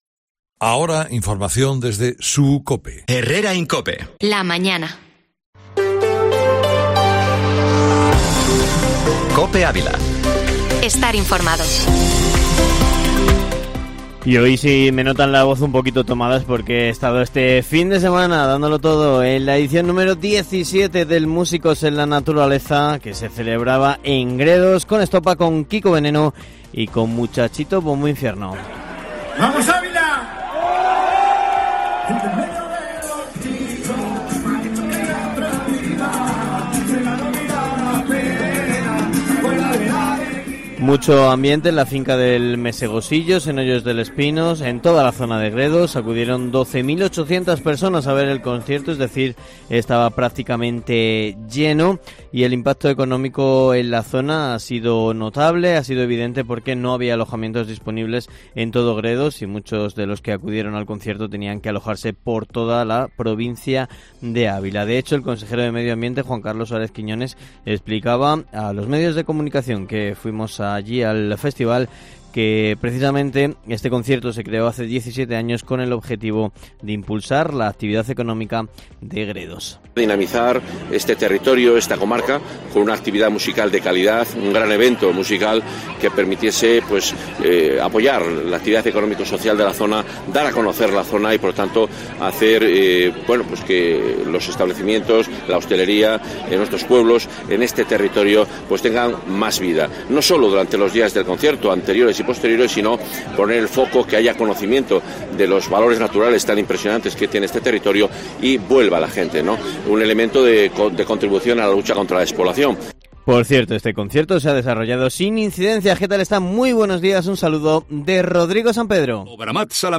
Informativo Matinal Herrera en COPE Ávila